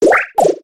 Grito de Cottonee.ogg
Grito_de_Cottonee.ogg.mp3